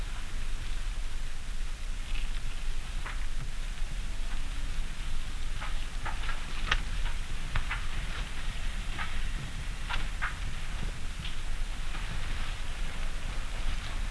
leavesrustling.wav